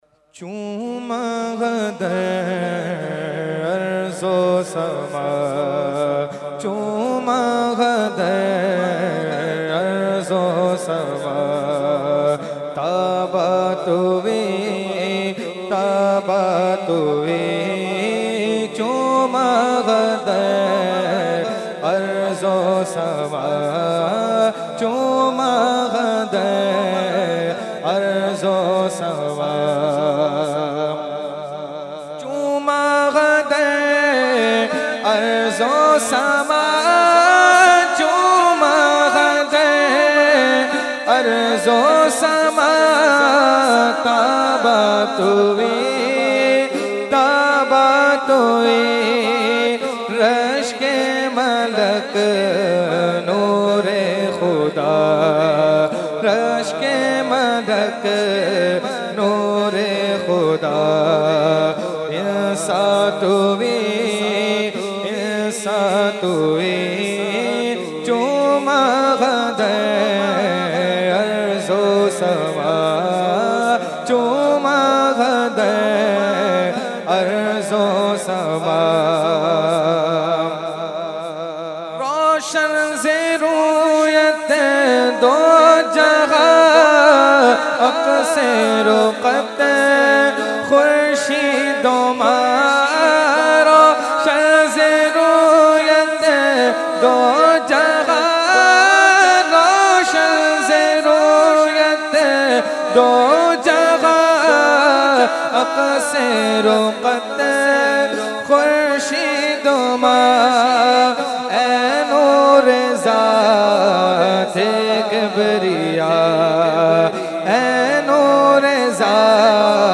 Category : Naat | Language : UrduEvent : Urs Qutbe Rabbani 2020